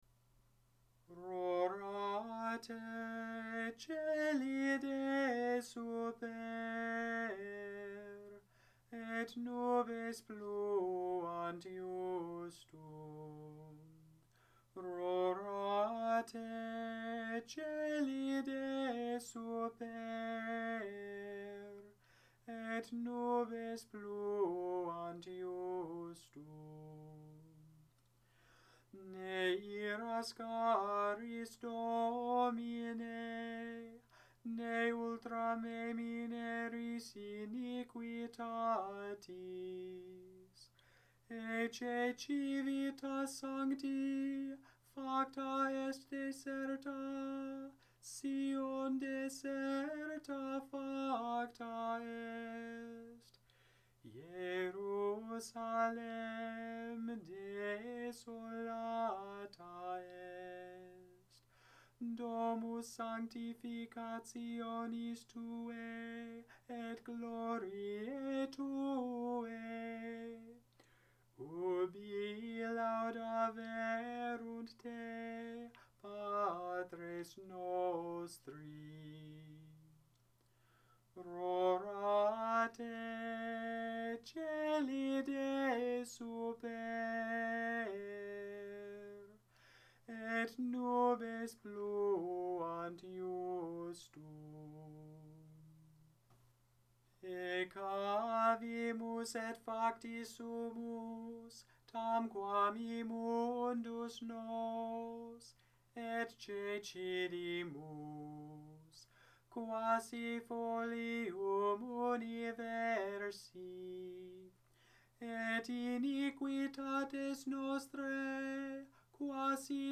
Gregorian, Catholic Chant Rorate Caeli
Use: General hymn for Mass, processions or private prayer